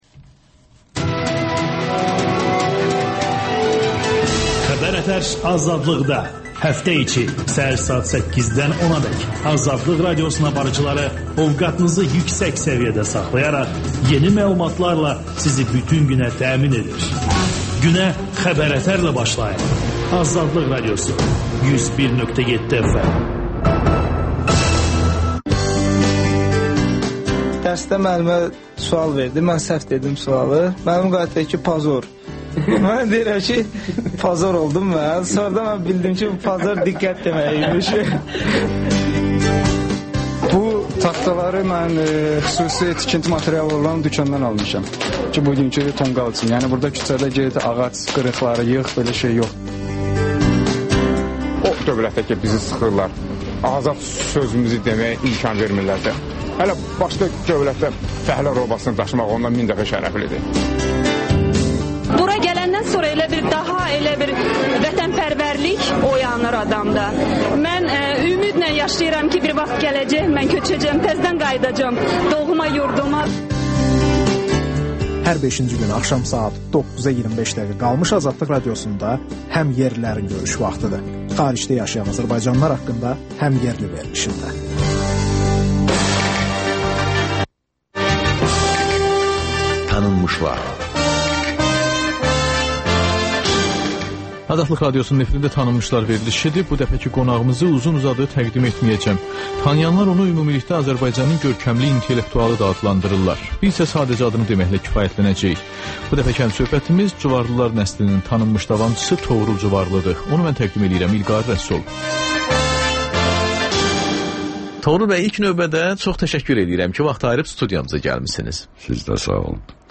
Ölkənin tanınmış simalarıyla söhbət (Təkrar)